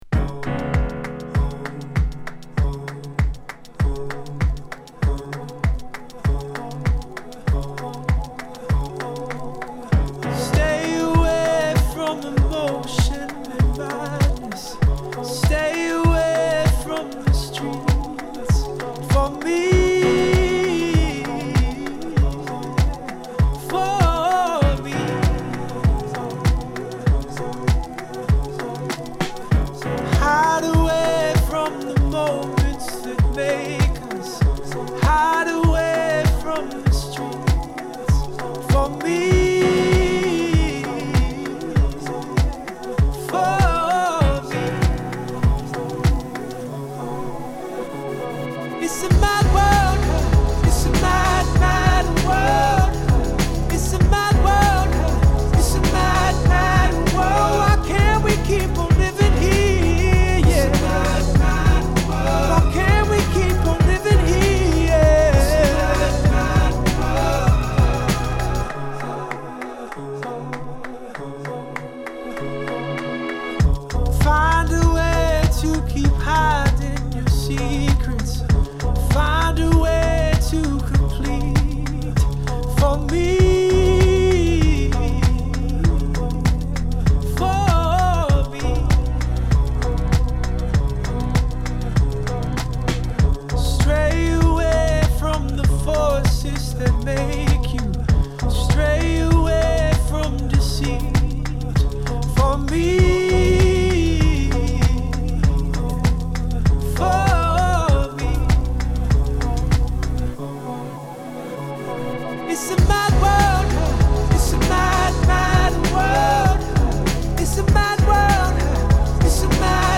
よりソウルフルで開放的な方向へと歩を進めた今作